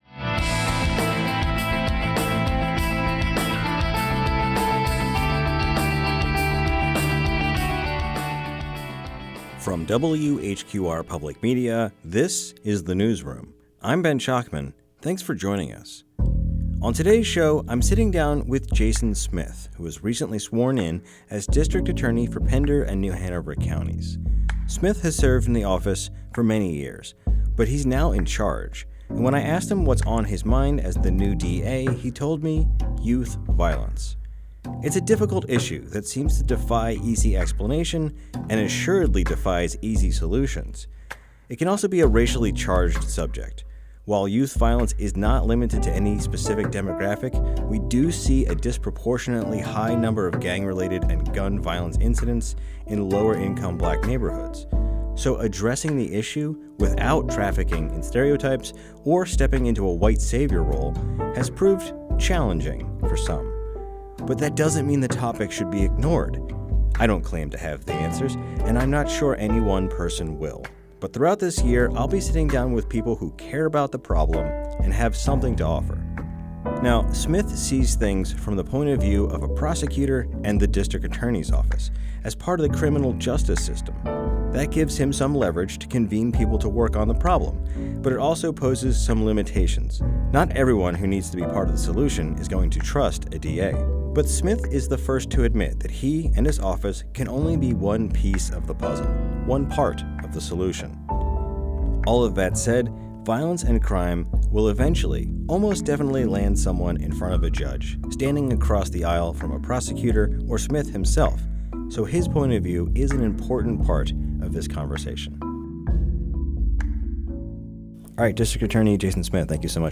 Sem a necessidade de instalar ou se inscrever A conversation with District Attorney Jason Smith about youth violence.